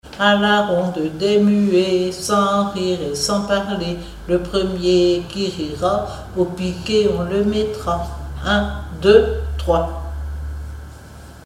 formulette enfantine : amusette
Pièce musicale inédite